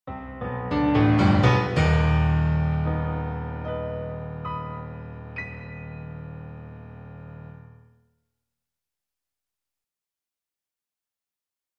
Music Logo; Solo Piano Tension.